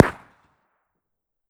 Improve ammo detonation sounds